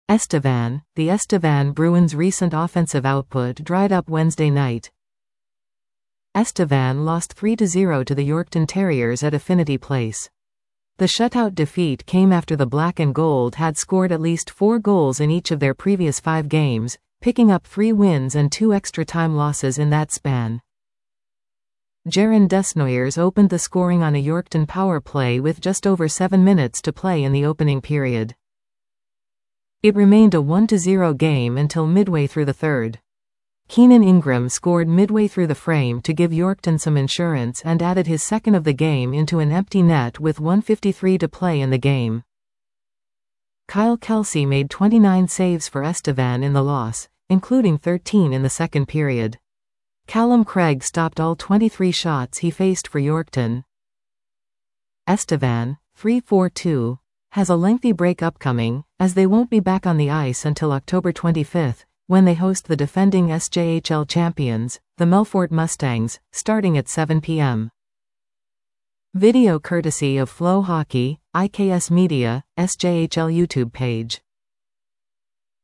Screen grab sourced from the SJHL YouTube page Listen to this article 00:01:15 ESTEVAN - The Estevan Bruins recent offensive output dried up Wednesday night.